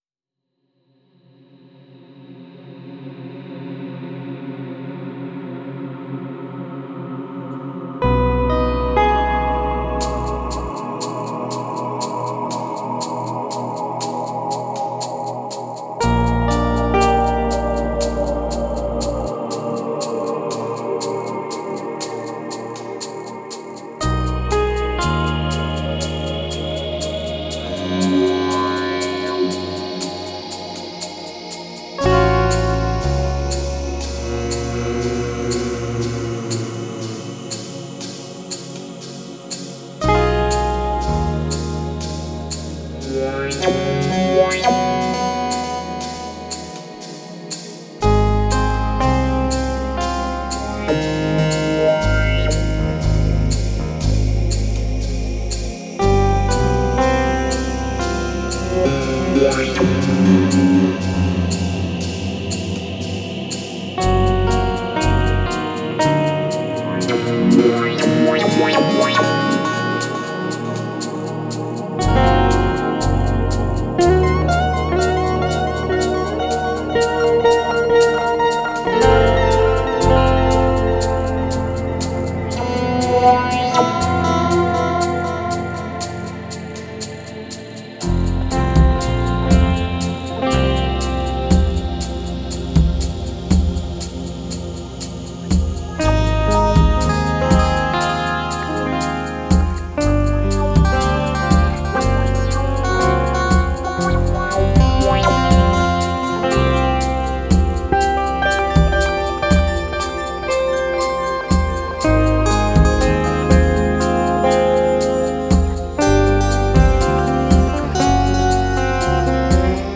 Alle Stücke auf dieser Seite liegen in CD-Qualität vor.
man beachte die unendliche Shepard-Tonleiter